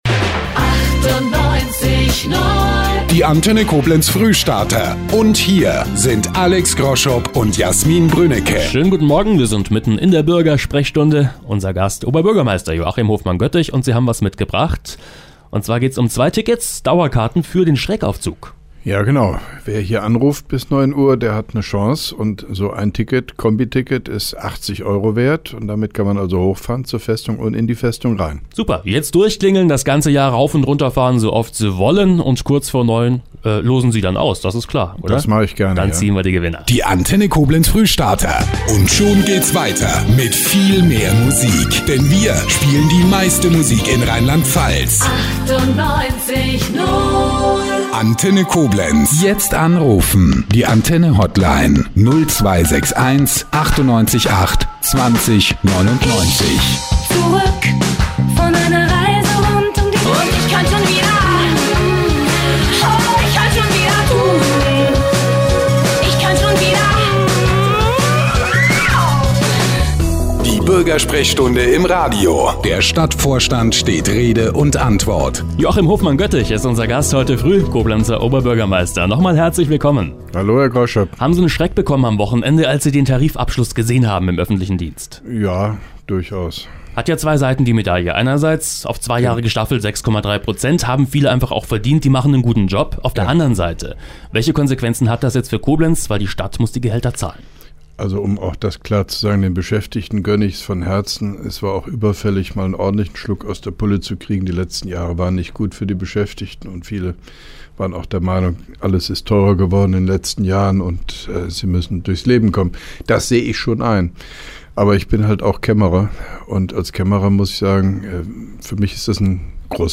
(2) Koblenzer Radio-Bürgersprechstunde mit OB Hofmann-Göttig 03.04.2012